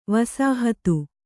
♪ vasāhatu